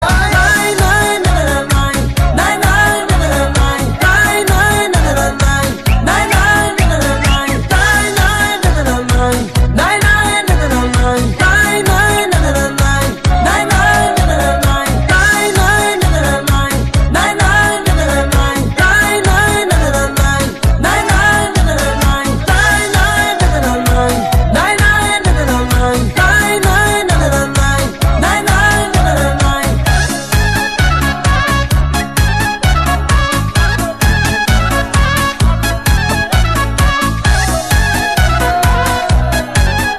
• Качество: 256, Stereo
громкие
веселые
восточные
Молдавские
румынские